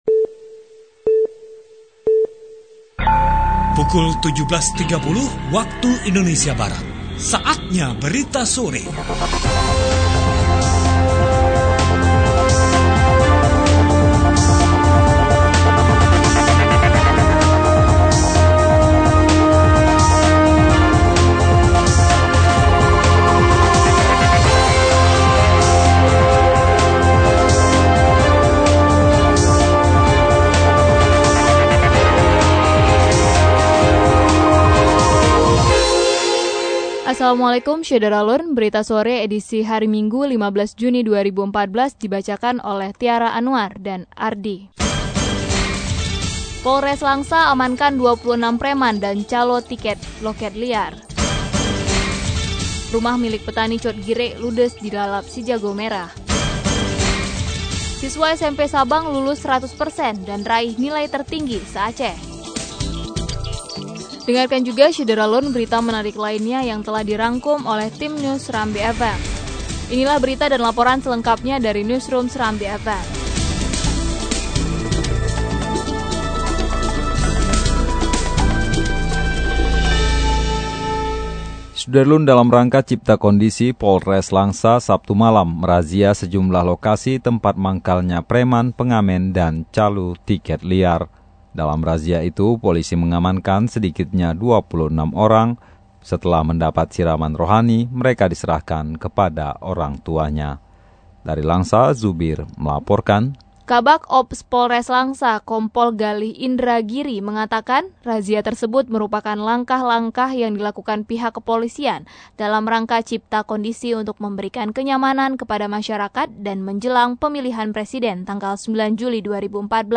Syedara Lon, berikut sari Berita Sore edisi Minggu,15 Juni 2014 :